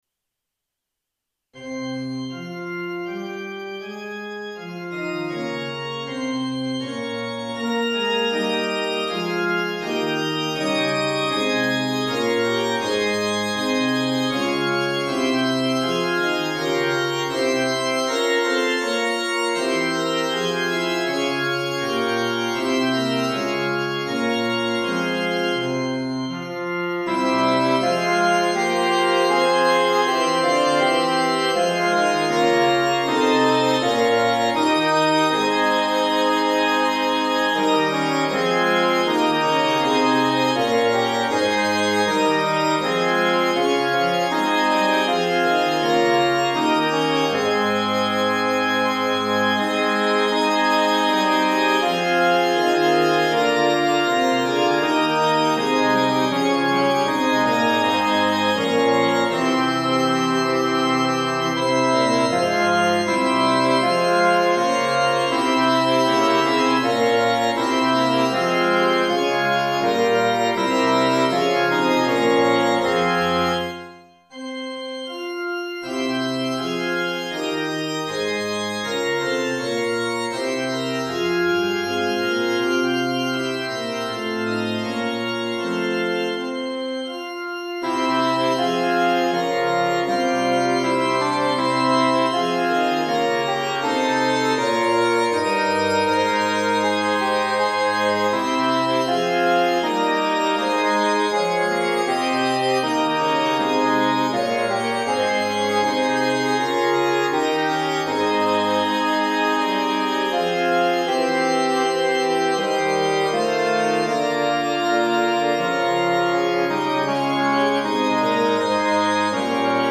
Fl Ob Cl Fg Org